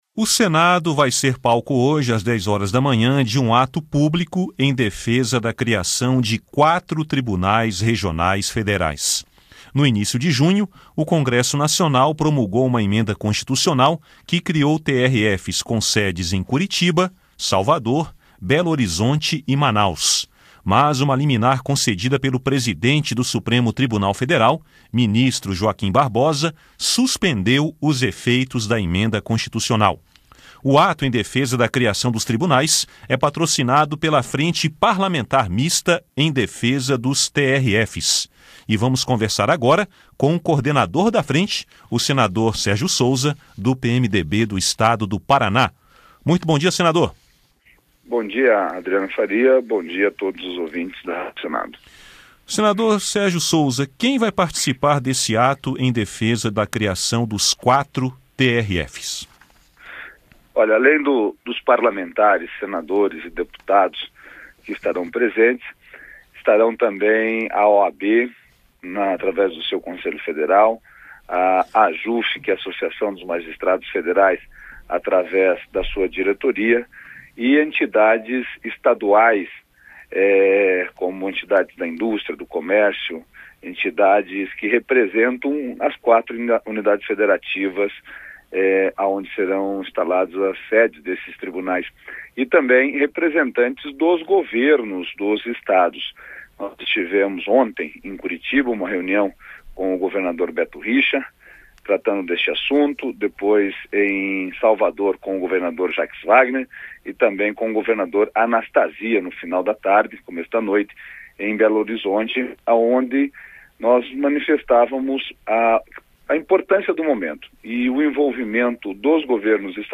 Entrevista com o senador Sérgio Souza (PMDB-PR), coordenador da Frente Parlamentar Mista em Defesa dos TRFs.